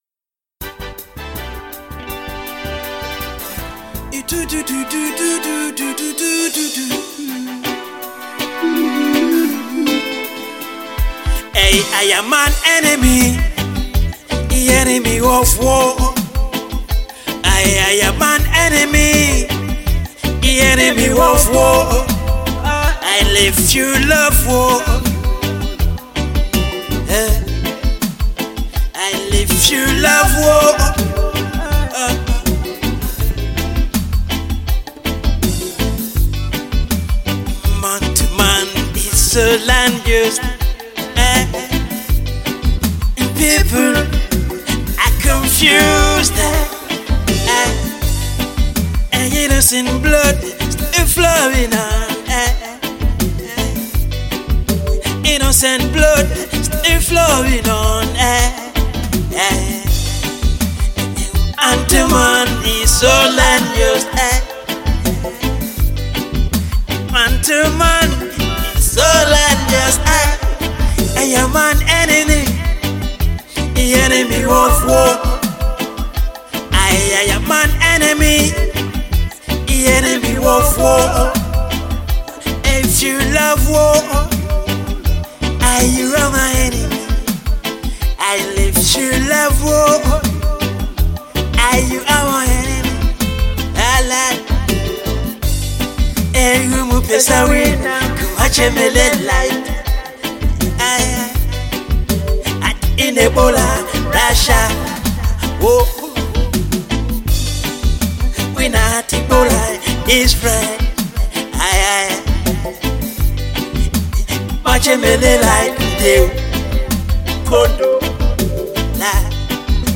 Genre : Reggae